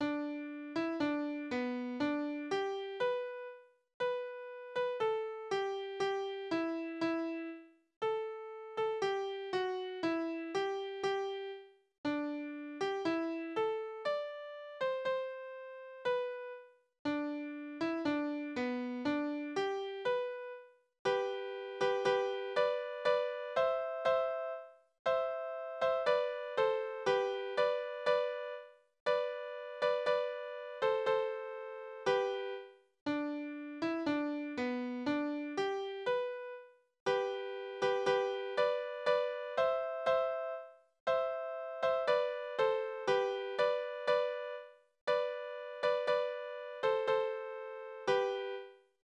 Liebeslieder: Gold und Silber
Tonart: G-Dur
Taktart: 2/4
Tonumfang: Oktave, Quarte
Besetzung: vokal